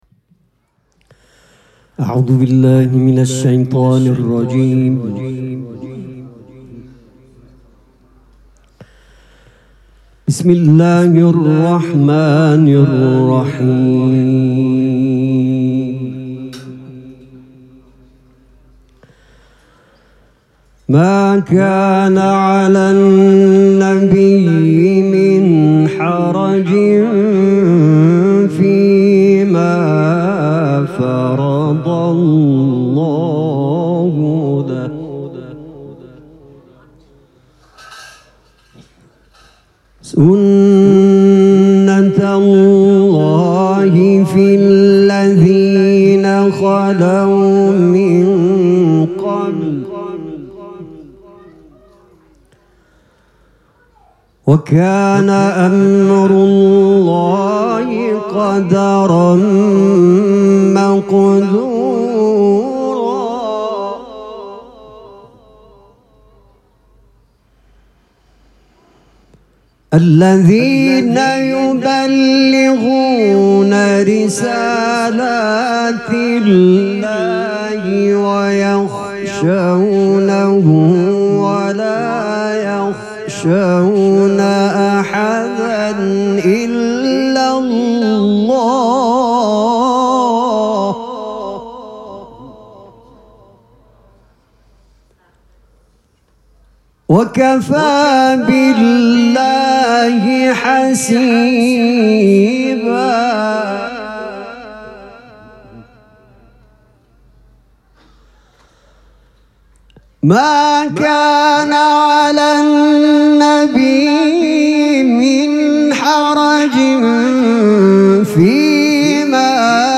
جشن ولادت حضرت رسول اکرم و امام صادق علیهماالسلام
حسینیه ریحانه الحسین سلام الله علیها
قرائت قرآن